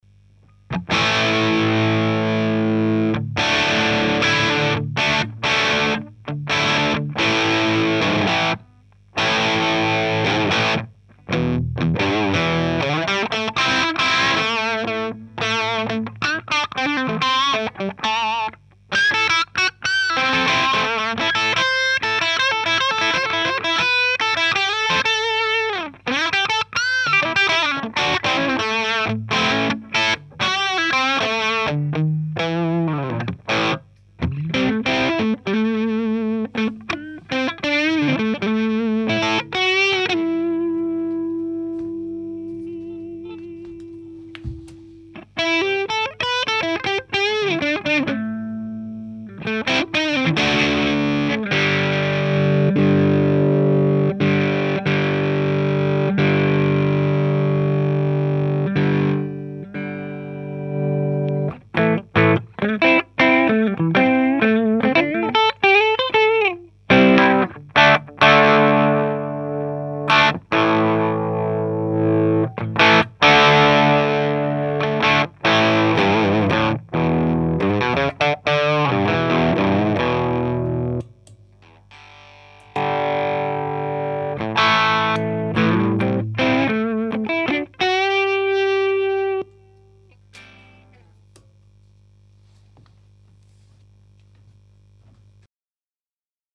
c'est une prise de son avec un Sm-58 devant le HP, un celestion classic lead 80, baffle 1x12 home made, preamp de la table de mix behringer, pas d'effort de placement particulier, parallele a la membrane du Hp, a 10cm environ ...
Sinon voici un autre sample, du canal plexi cette fois :
Pareil, un peu trop harsh a mon gout, il faudra que je teste sur mon autre baffle (fermé, en V30), ou sur un 1936 de marshall (2xG12T75 fermé).